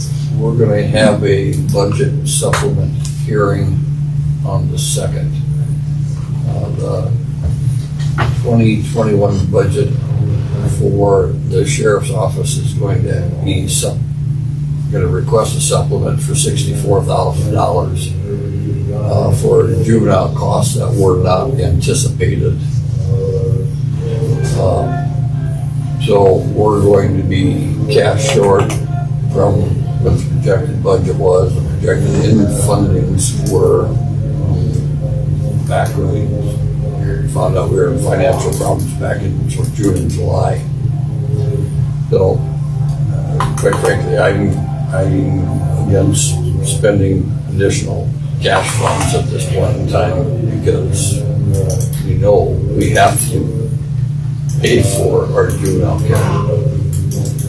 The commission discussed Commissioner Schilling’s motion to advertise for an Extension/4-H Youth Advisor position.  Commissioners Jim Houck and Duane Mohr suggested meeting with Campbell County before making a decision to advertise.  Commissioner Cain talked about the issue with regards to an upcoming hearing for a budget supplement.